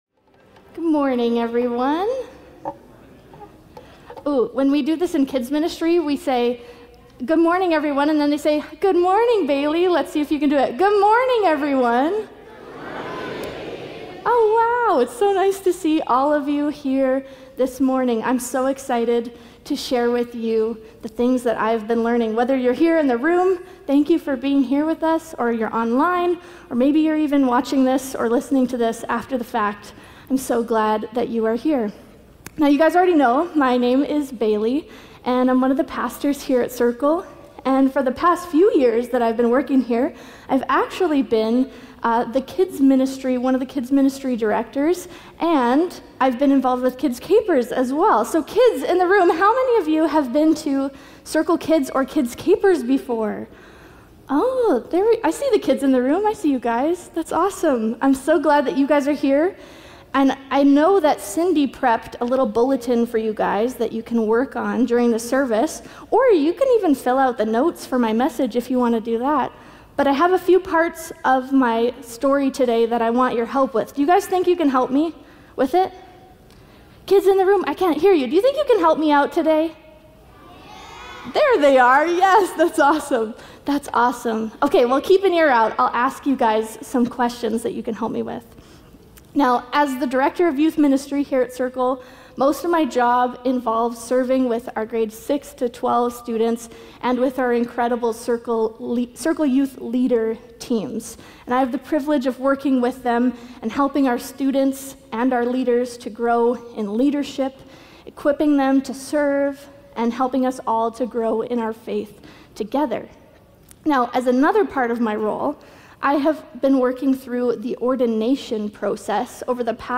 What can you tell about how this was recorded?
Sunday morning talks from Circle Drive Alliance in Saskatoon.